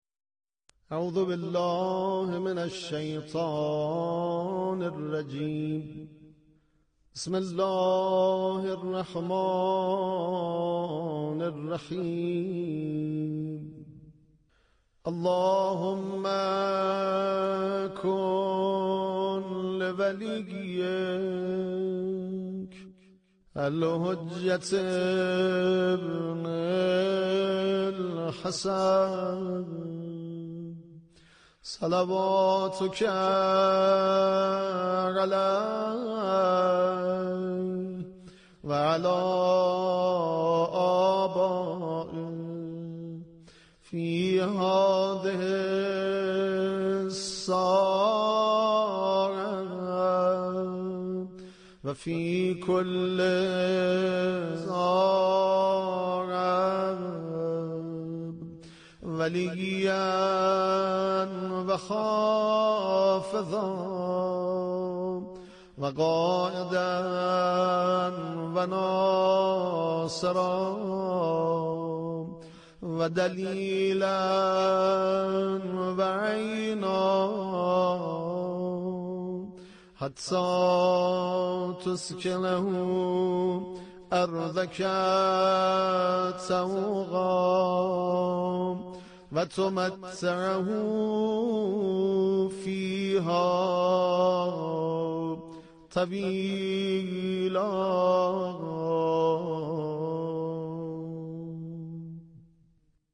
دعای فرج و سلامتی امام زمان (عج)